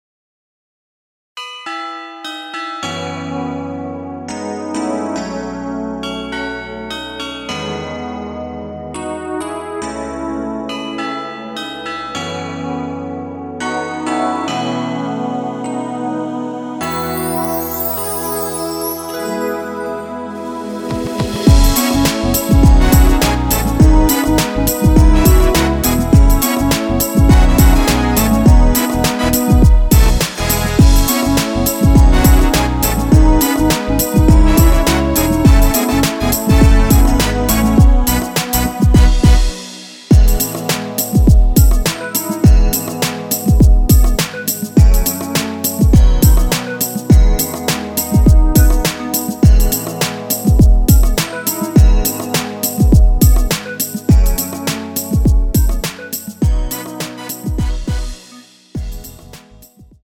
원키에서(-6)내린 MR입니다.
앞부분30초, 뒷부분30초씩 편집해서 올려 드리고 있습니다.
중간에 음이 끈어지고 다시 나오는 이유는